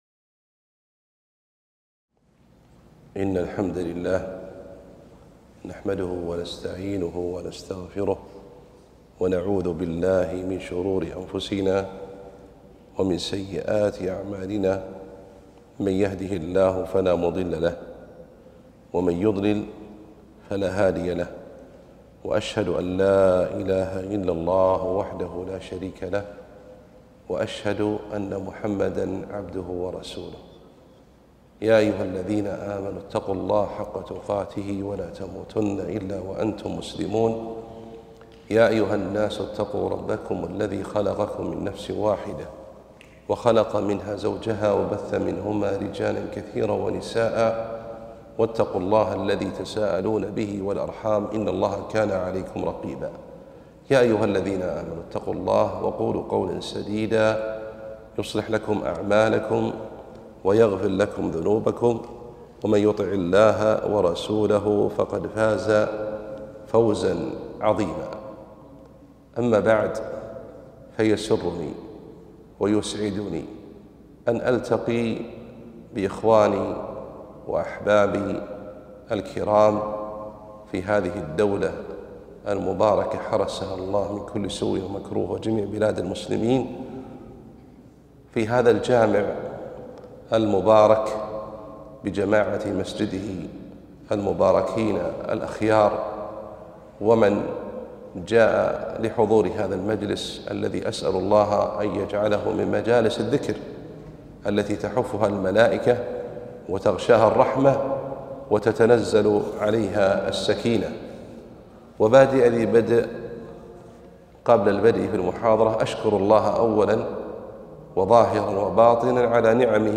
محاضرة - تعظيم التوحيد